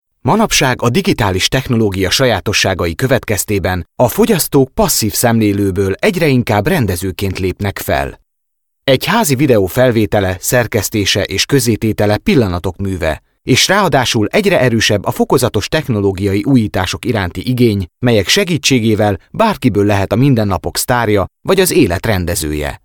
Native speaker Male 20-30 lat
Young-sounding Hungarian voiceover voice.
Nagranie lektorskie
demo_documentary_HUN.mp3